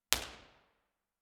Room Survey 21 – Hotel Meeting Room Line Arrays
Description: Hotel meeting room. Active vs. passive line array comparison.
IR_TP2_Passive.wav